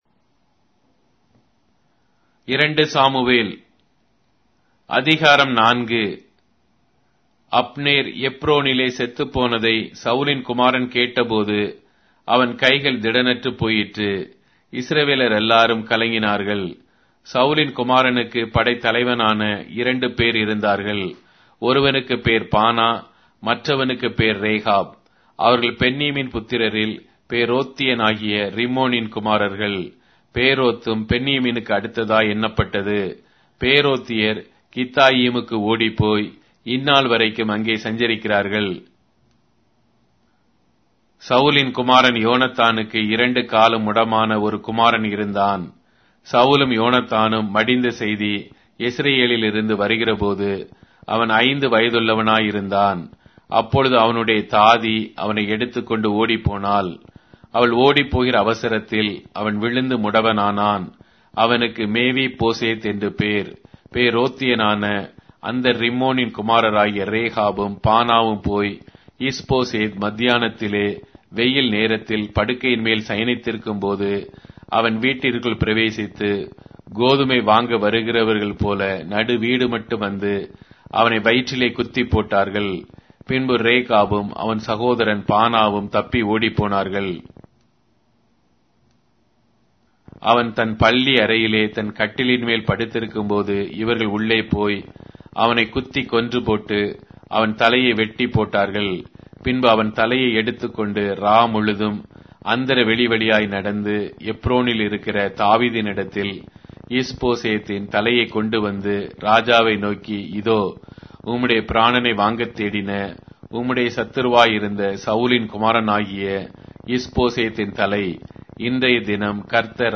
Tamil Audio Bible - 2-Samuel 17 in Hcsb bible version